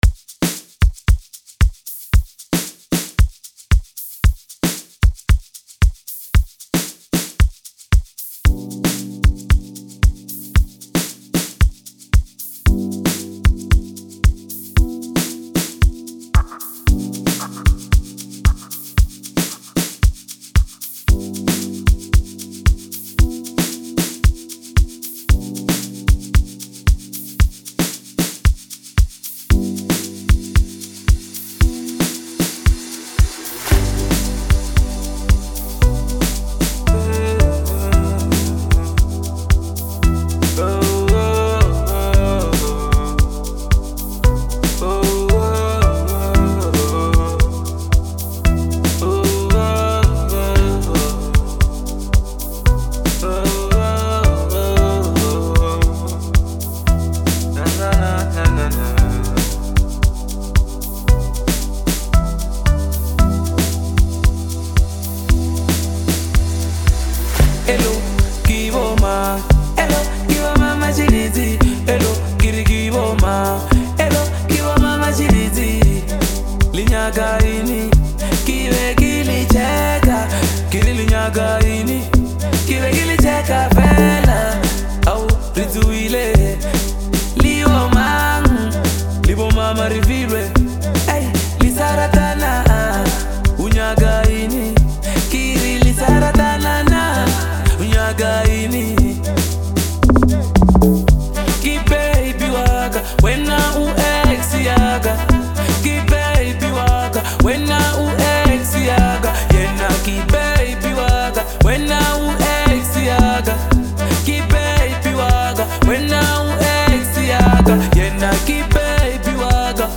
Amapiano
With infectious beats and smooth melodies